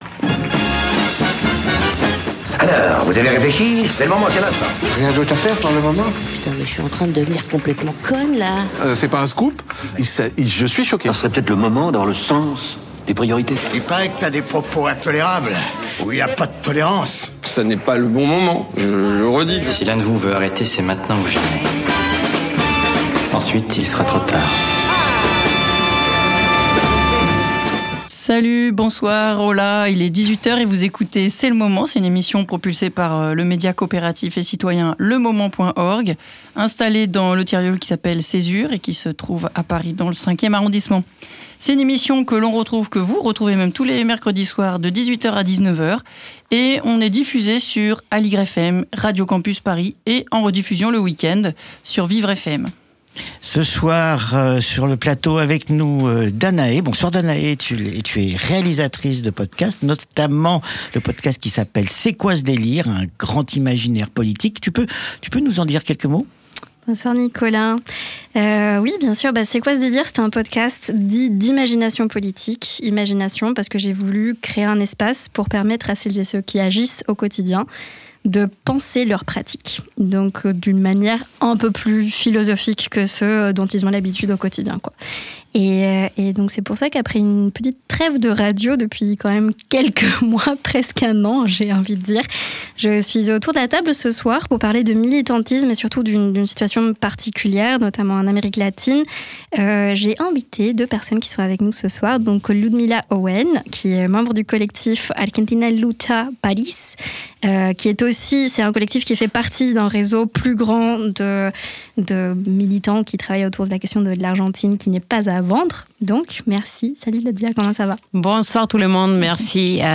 « C’est le moment », émission hebdomadaire d’actualité diffusée sur Aligre FM, Radio Campus Paris et Vivre FM.